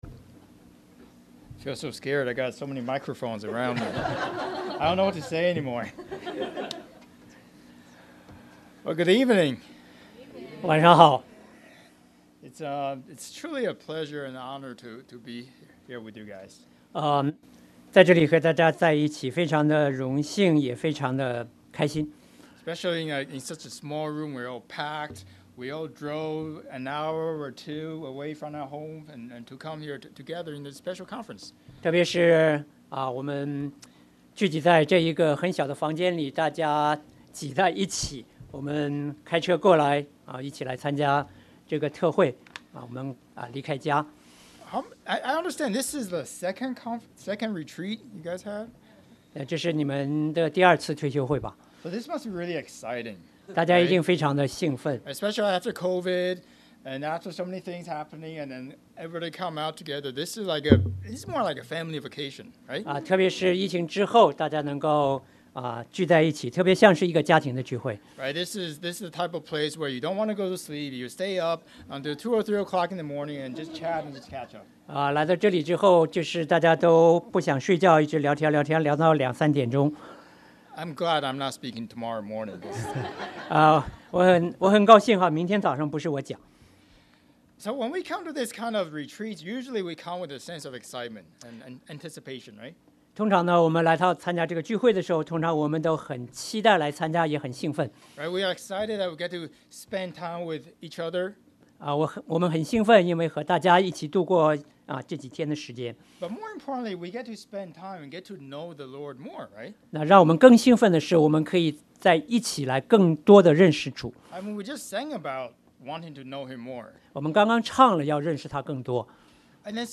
2024 Thanksgiving Retreat